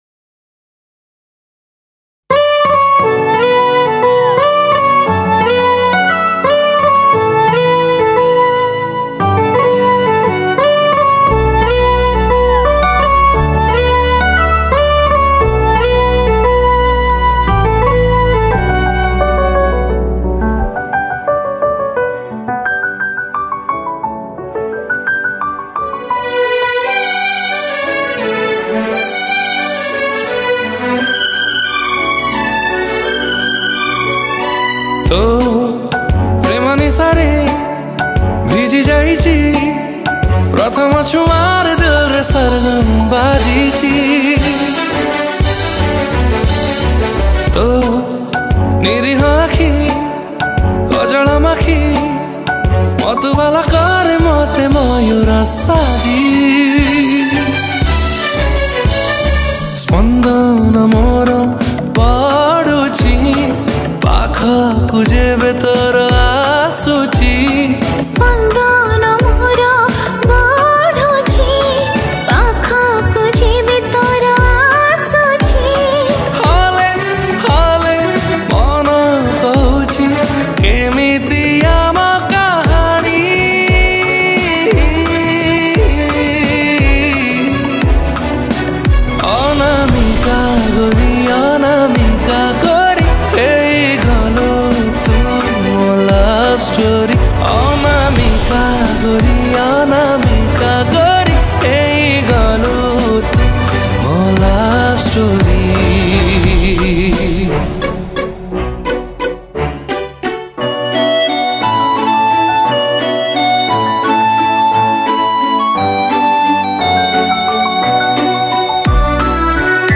Odia New Romantic Song